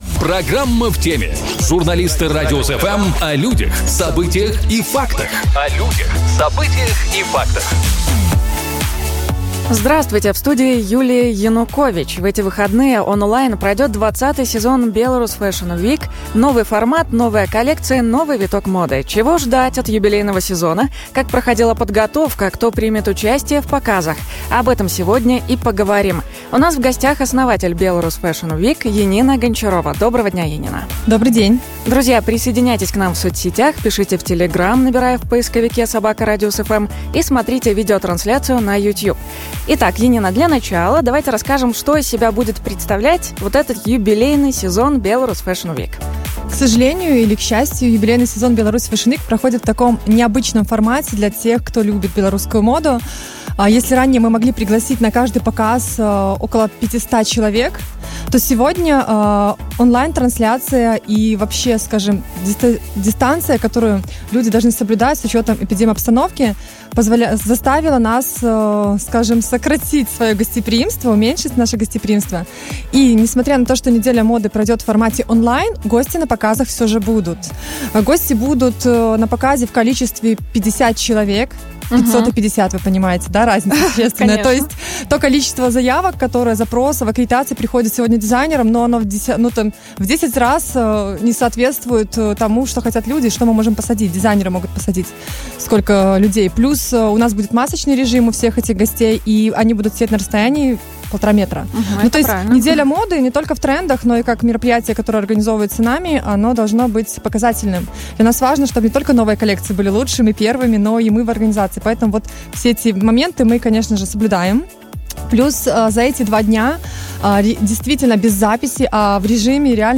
У нас в гостях